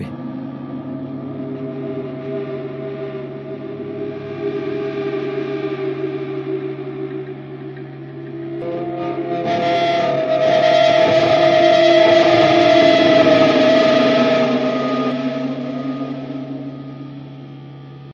Electric guitar sounds. Through delay/reverb. Slowly bending strings. Sounds fading in and out. Slow screams from the guitar strings.